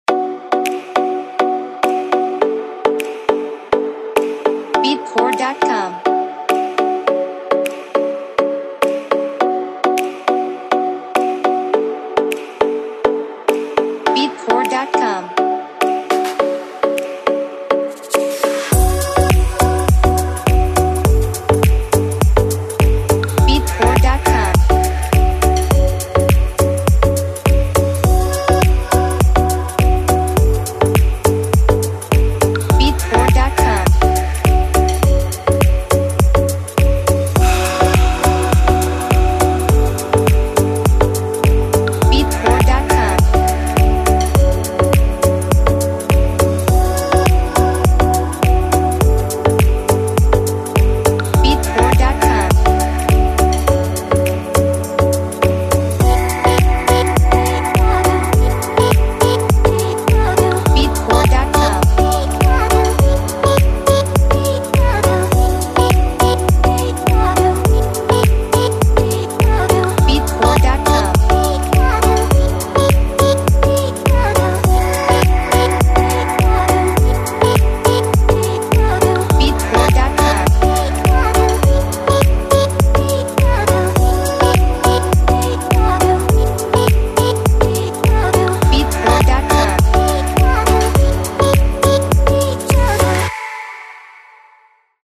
Instruments: Synthesizer Vocal